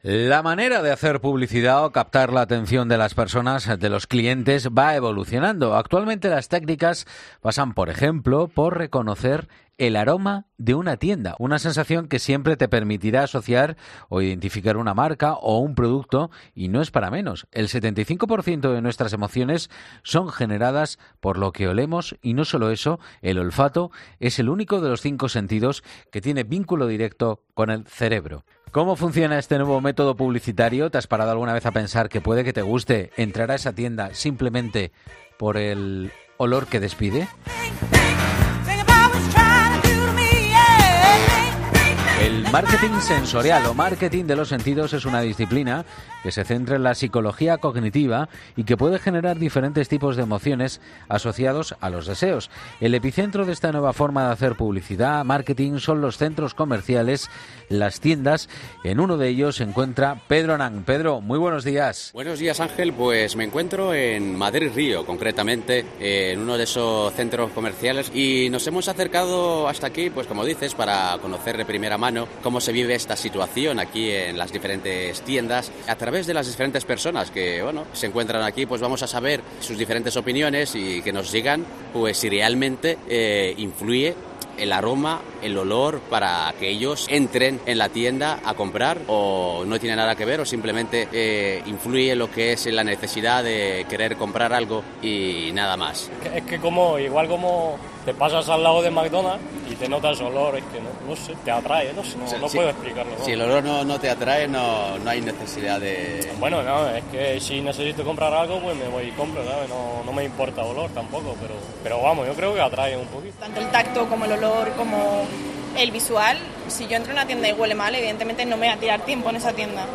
En este reportaje, hemos querido centrarnos en el imnpacto que causa en las personas las diferentes fragancias o aromas que desprenden las tiendas y hasta qué punto estos olores pueden llegar a influir en nuestra decisión.
Así nos lo aseguraba una de las personas encuestadas.